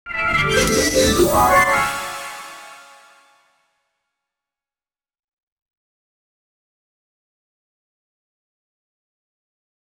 Royalty-free audio-logo sound effects
catchy-audio-logo--jbthjc2r.wav